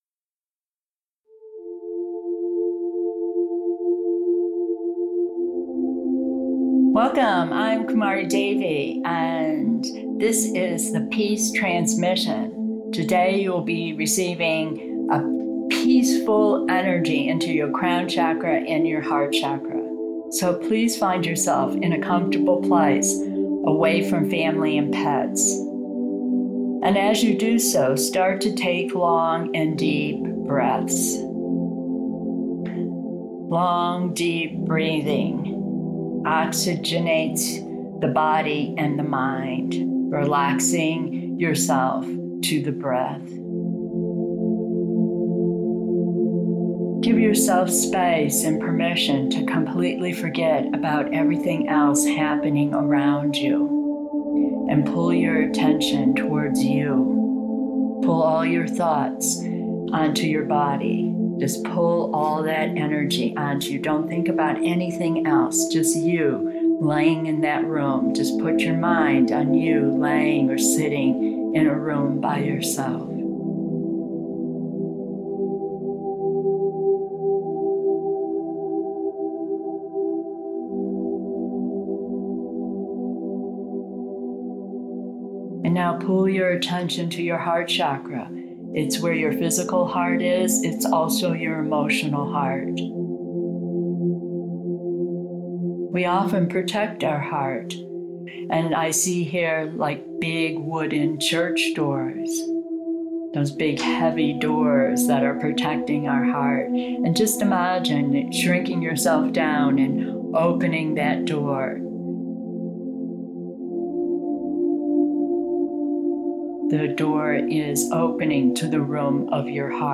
Calmness Guided Practice